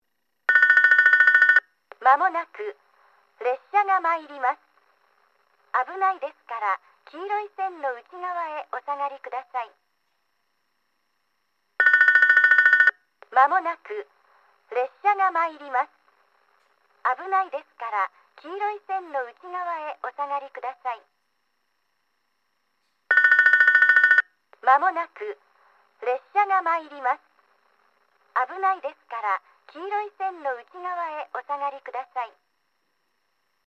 接近放送　女声   かつては九州カンノ型Ａのカラカラベルでした。
スピーカーはTOAラッパ型でした。